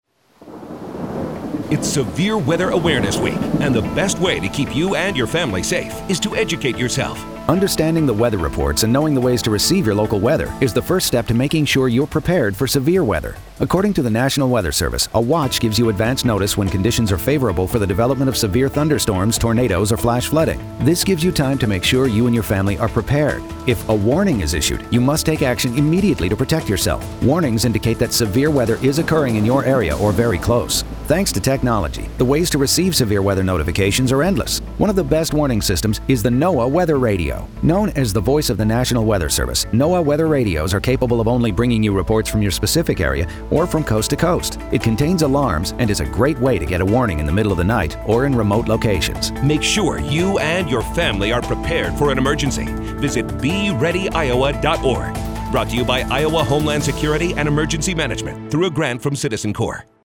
PSA_SWAW_Warnings.mp3